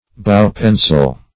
Bow-pencil \Bow"-pen`cil\, n. Bow-compasses, one leg of which carries a pencil.